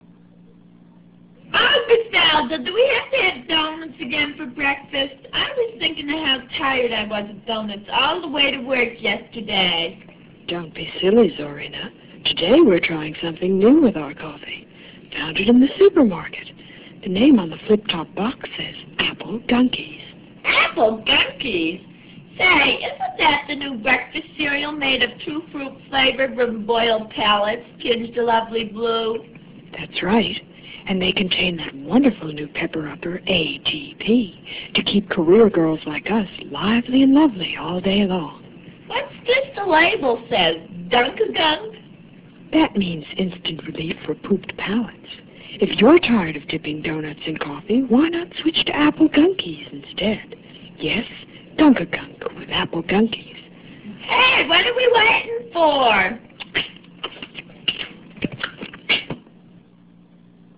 I decided it would be fun to run fake humorous commercials instead.